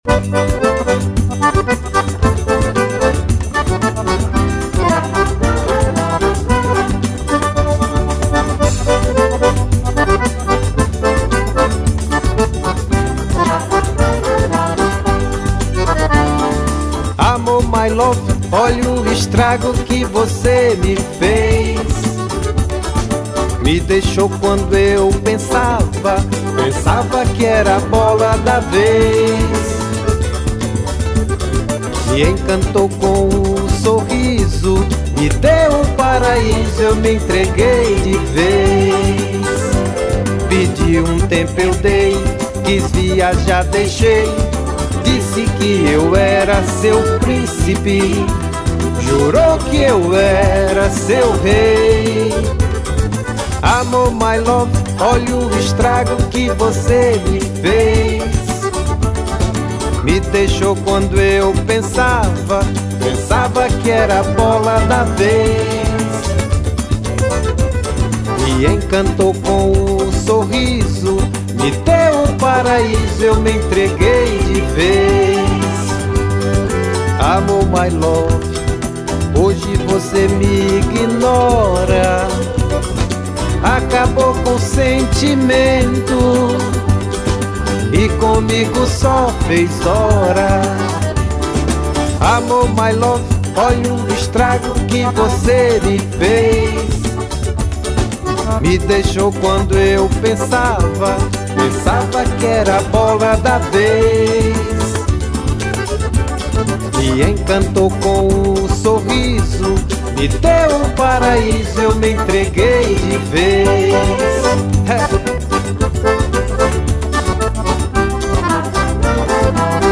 MÚSICA POPULAR NORDESTINA
Acoordeon